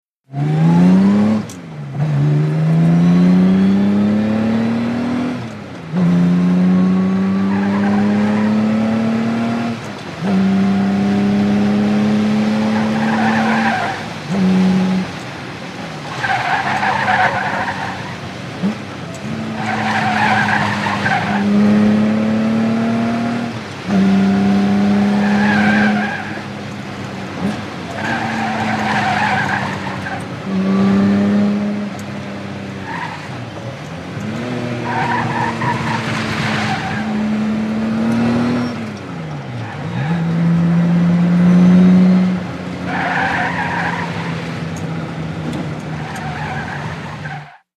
VEHICLES ASTON MARTIN: INT: Fast driving with squealing tires.